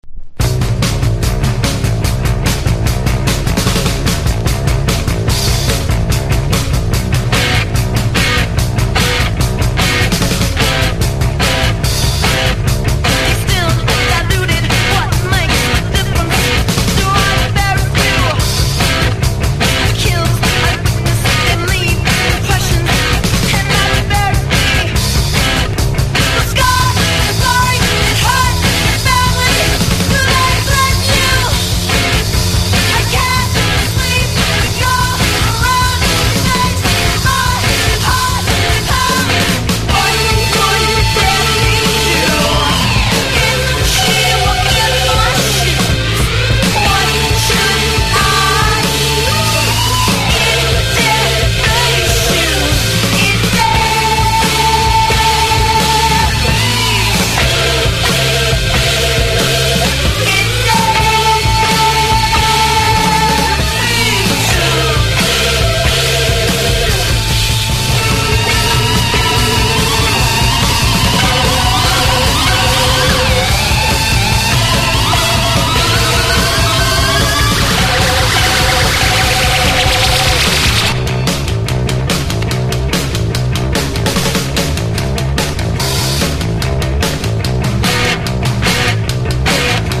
1. 00S ROCK >
オリジナルは、パワフルなガールズ･パンキッシュ･チューン。
ドープなエレクトロ･ファンクに仕上がってます。
(ORIGINAL EDIT)